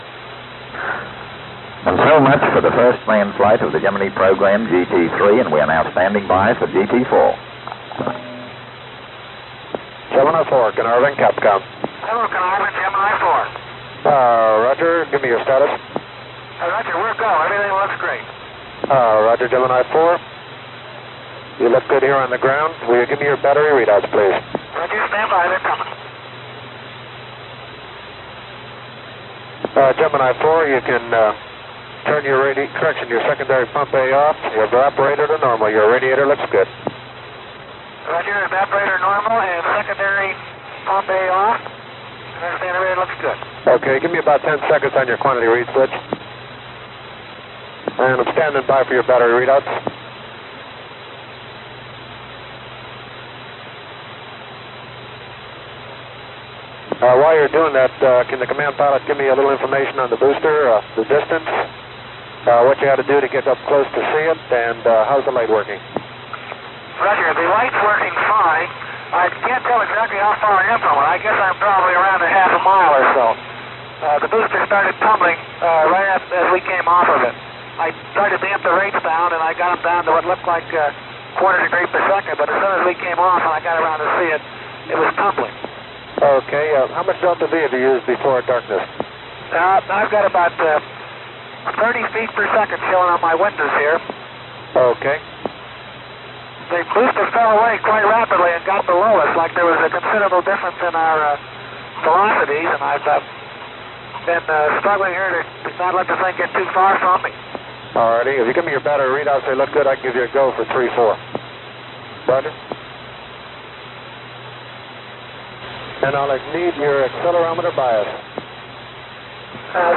At the end of the Gemini series, a compilation tape of Carnarvon clips from all the manned missions (with the exception of GT10), was produced.